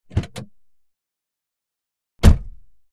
Lincoln Towncar Door Slams, In Garage And Outdoors